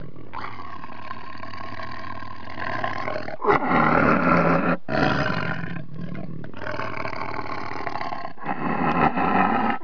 جلوه های صوتی
دانلود صدای حیوانات جنگلی 88 از ساعد نیوز با لینک مستقیم و کیفیت بالا